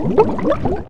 bubble.wav